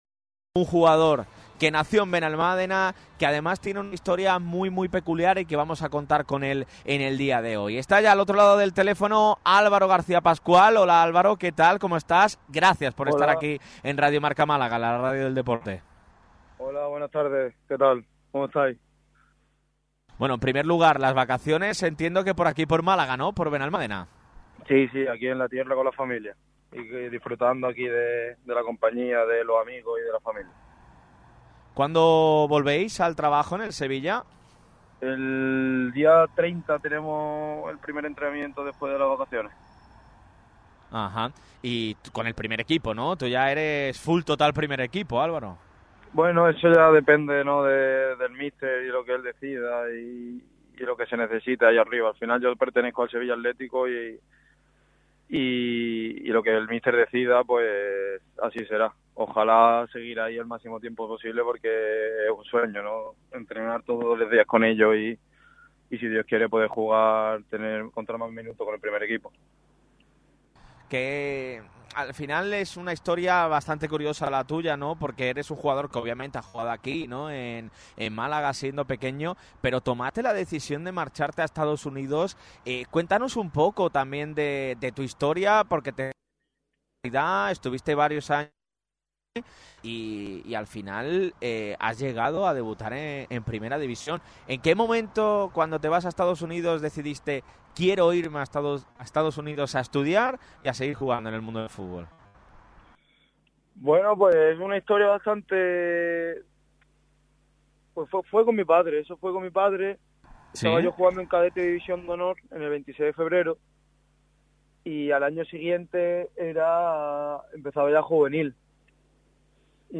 ha atendido en una entrevista exclusiva al micrófono rojo de Radio MARCA Málaga.